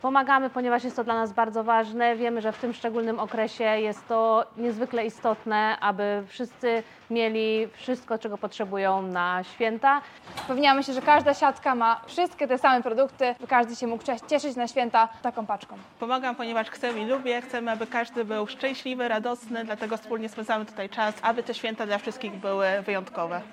Wolontariuszki przygotowują obecnie kolejne paczki, które trafiają do potrzebujących osób. Jak podkreślają, to wielka radość pomagać innym.
s5-wolontariusze.mp3